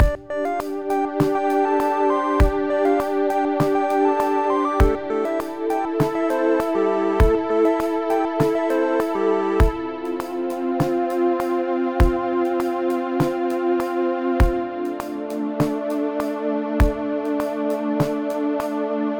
click track
clicks.wav